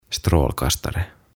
Tuhat sanaa ruotsiksi - Ääntämisohjeet